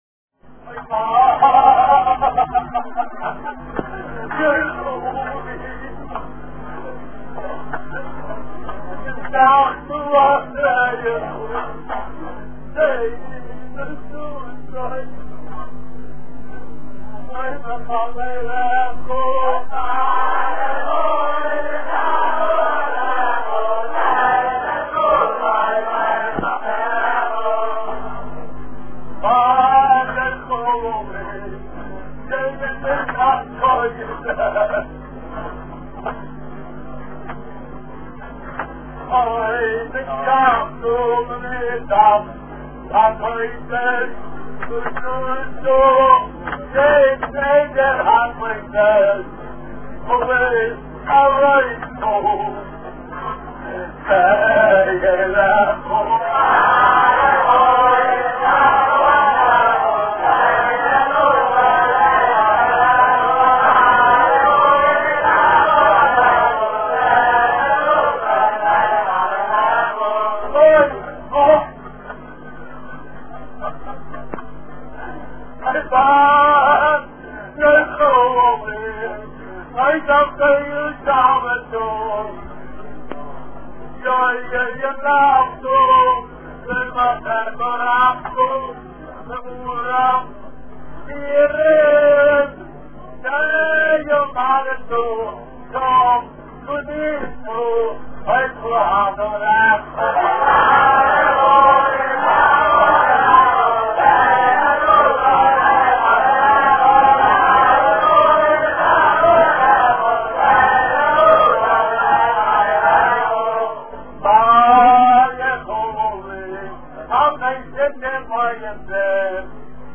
ארכיון: ל"ג בעומר הילולא דרשב"י במחיצת רבוה"ק - ויז'ניצע נייעס
בשירת בר יוחאי